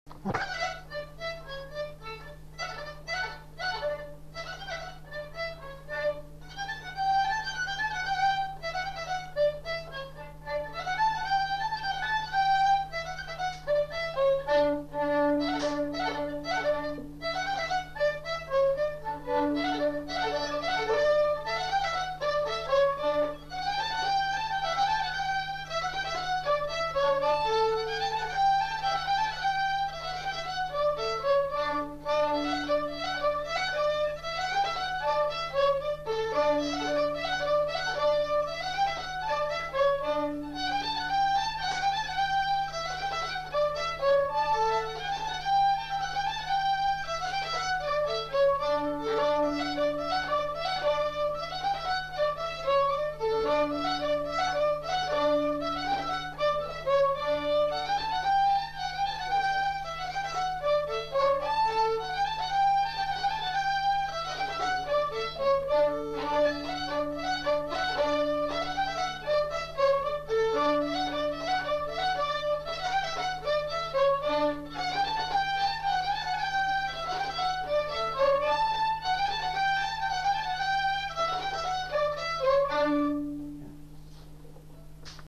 Lieu : Saint-Michel-de-Castelnau
Genre : morceau instrumental
Instrument de musique : violon
Danse : rondeau
Notes consultables : 2 violons.